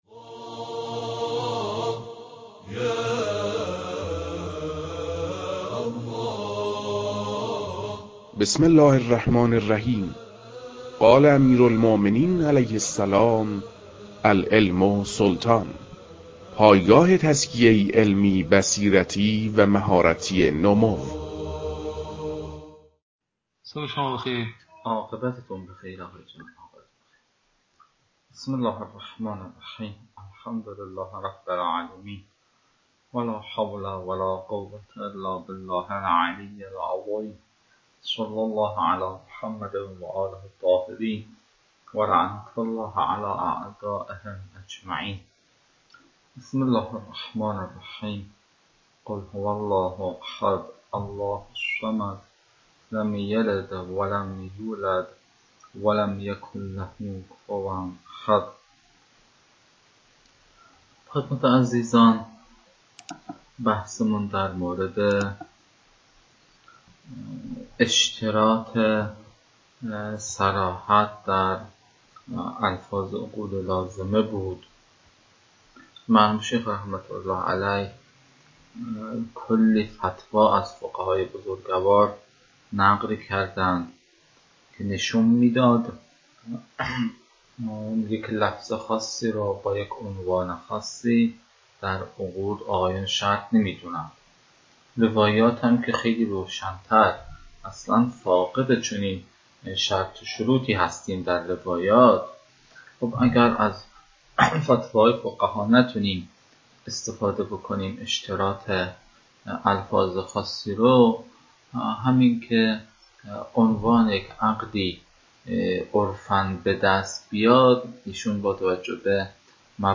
در این بخش، فایل های مربوط به تدریس مباحث تنبیهات معاطات از كتاب المكاسب